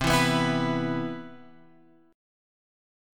C Major 7th